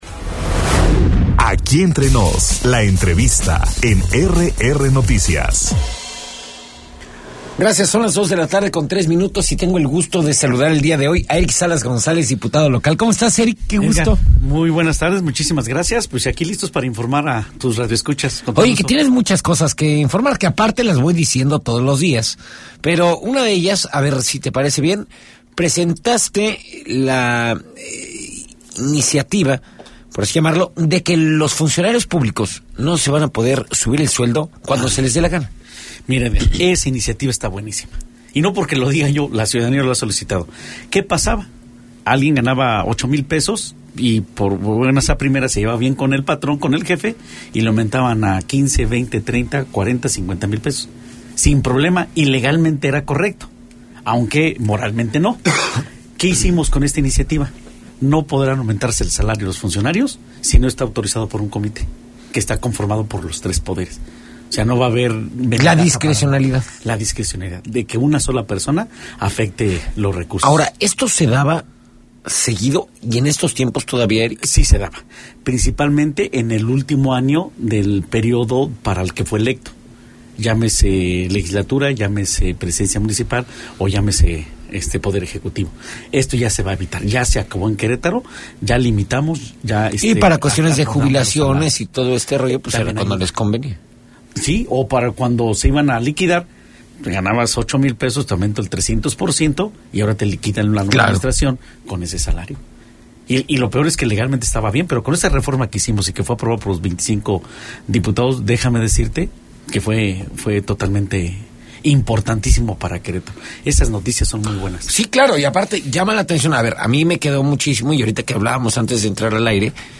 El diputado local Eric Salas González, en entrevista